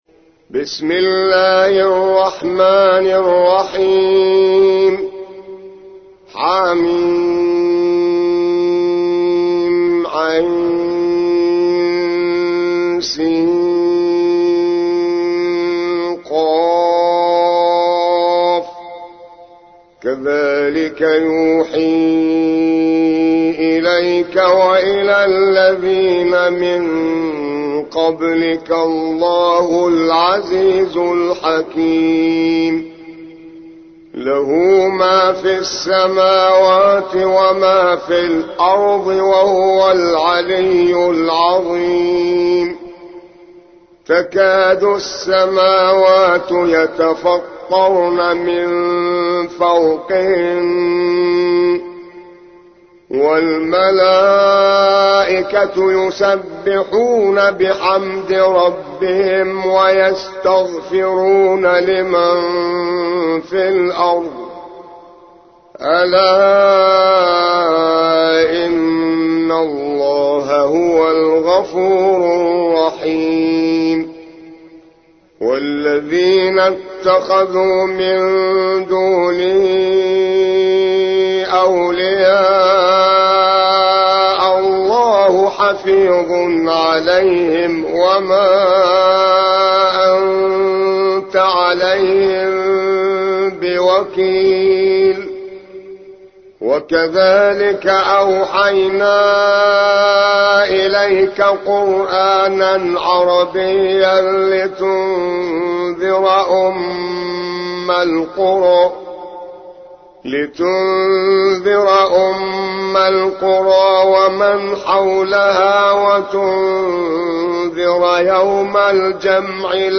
42. سورة الشورى / القارئ